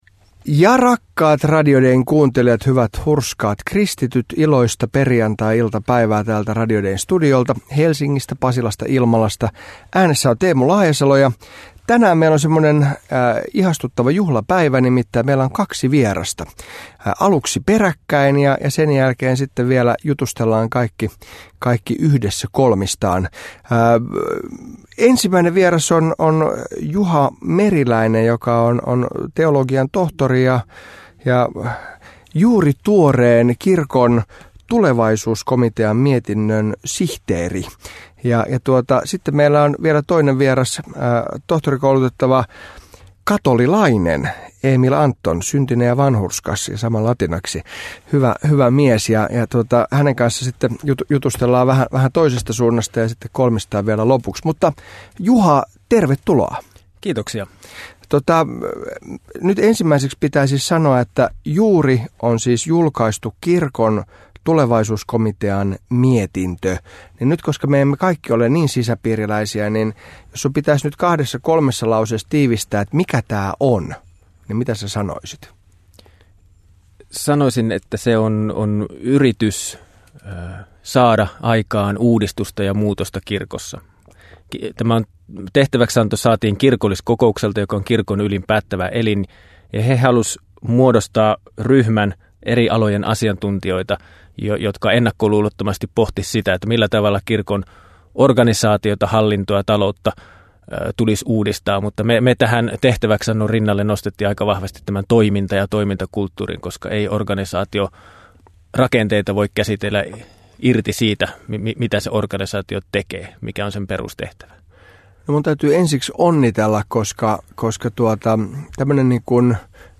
Molemmat teologit ovat kirkkoherra Teemu Laajasalon haastattelussa pe 21.10. klo 16. sekä ti 25.10. klo 19.